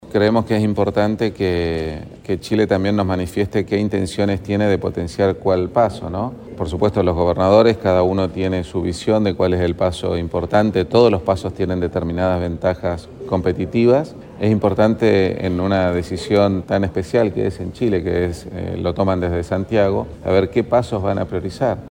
Fue el Salón Mural del Gobierno Regional el que albergó la reunión.